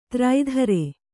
♪ traidhare